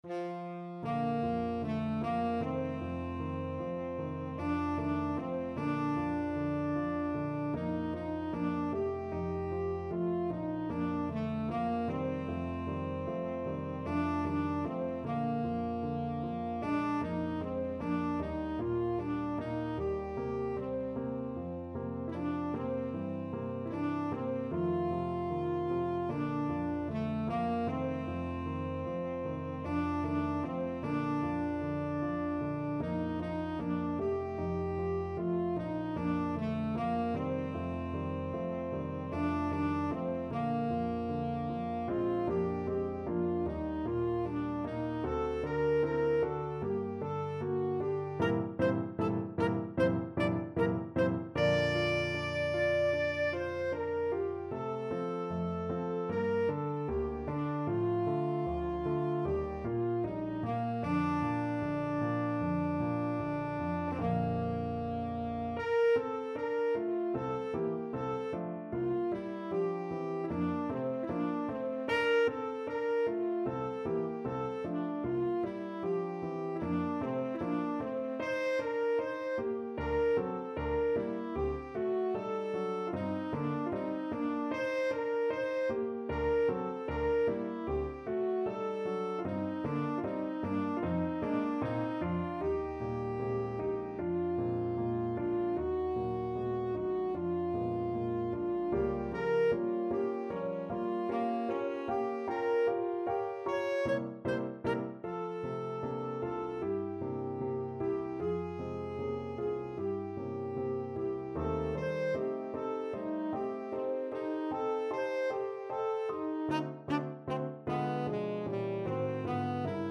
Tenor Saxophone
Bb major (Sounding Pitch) C major (Tenor Saxophone in Bb) (View more Bb major Music for Tenor Saxophone )
2/4 (View more 2/4 Music)
Lento ma non troppo = c.76
Eb4-D6
Classical (View more Classical Tenor Saxophone Music)